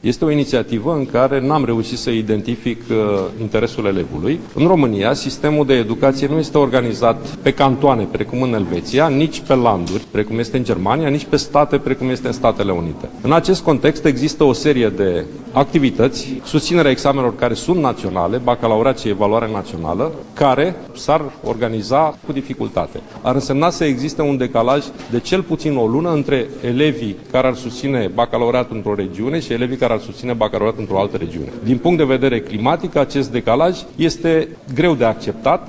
Ministrul Sorin Cîmpeanu spune că o astfel de măsură ar îngreuna organizarea examenelor naționale și ar înseamna ca unii elevi, dintr-o anumită regiune, să dea Bacalaureatul la distanță de o lună față de alți colegi: